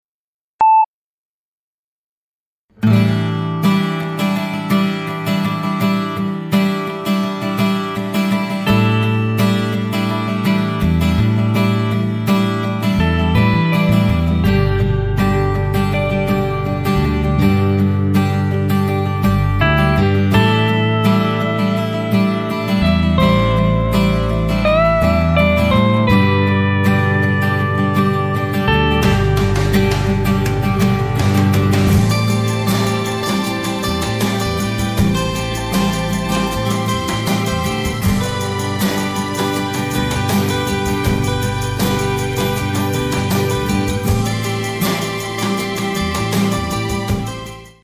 Chandón (Radio)